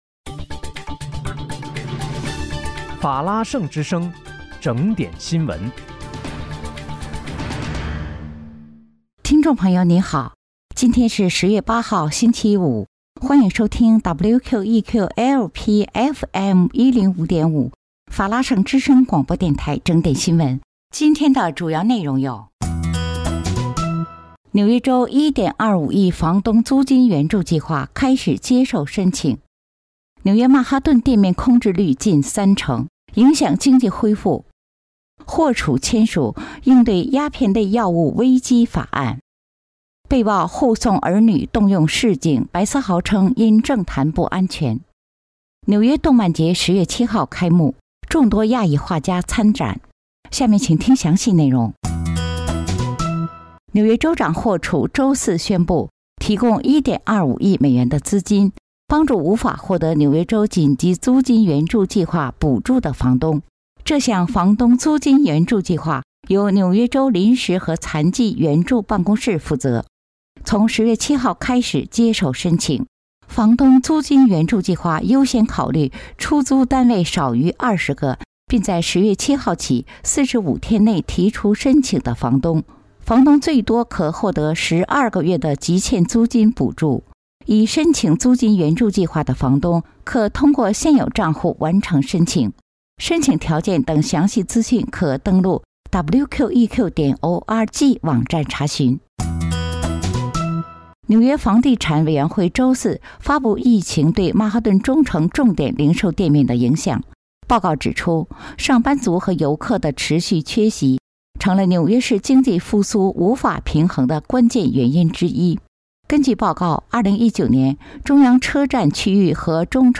10月8日（星期五）纽约整点新闻